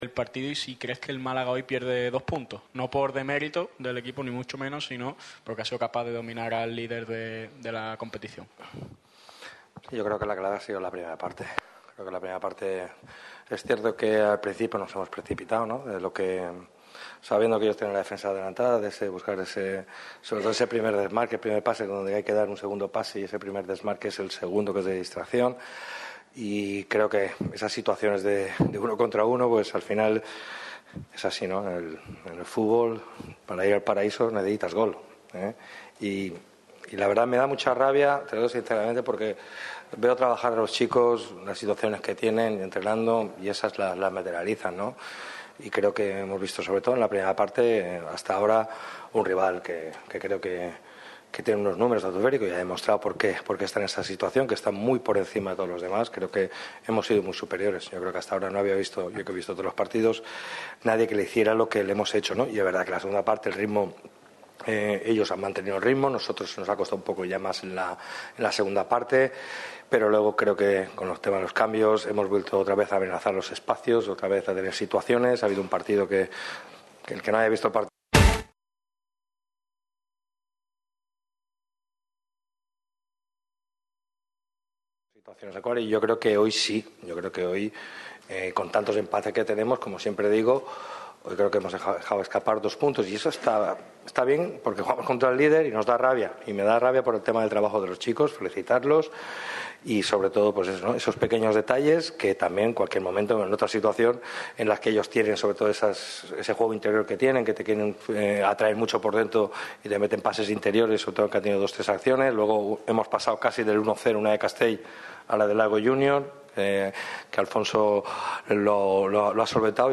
El entrenador del Málaga CF, Sergio Pellicer, ha comparecido en rueda de prensa tras el serio partido de su equipo ante el imponente líder, el Racing de Santander. El técnico de Nules ha hablado del punto obtenido y la efectividad que de momento falta en el equipo.